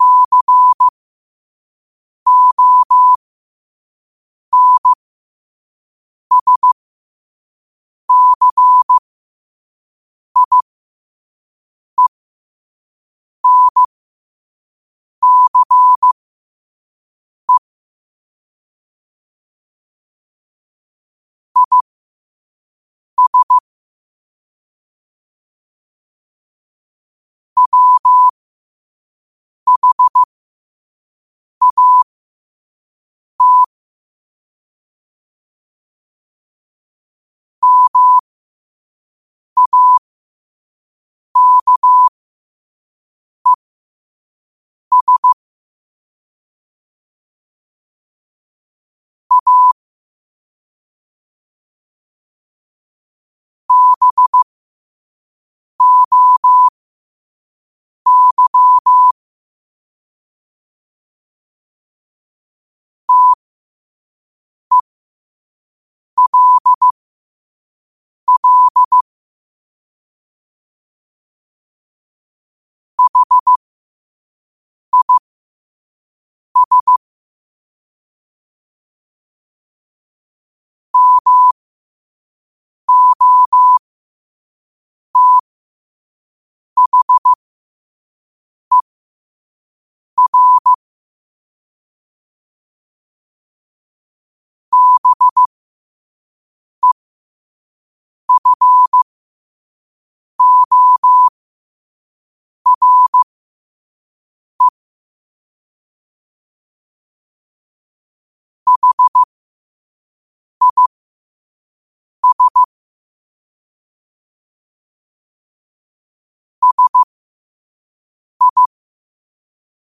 5 WPM morse code quotes for Tue, 12 Aug 2025 by QOTD at 5 WPM
Quotes for Tue, 12 Aug 2025 in Morse Code at 5 words per minute.